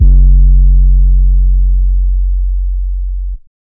Sizzle [808].wav